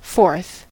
forth: Wikimedia Commons US English Pronunciations
En-us-forth.WAV